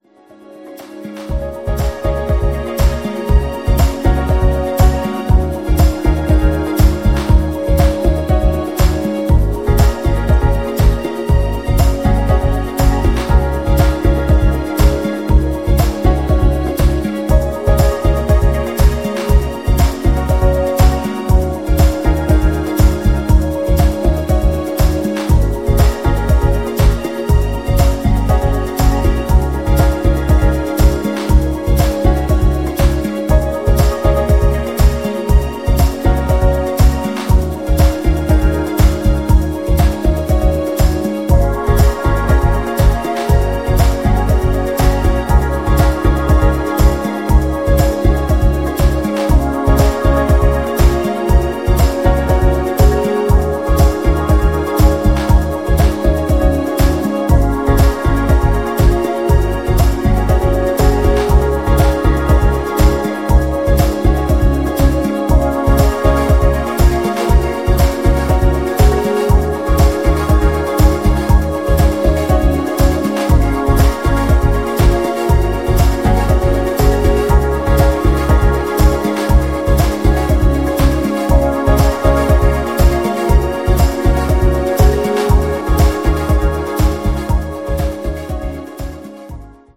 ジャンル(スタイル) BALEARIC / BALEARIC HOUSE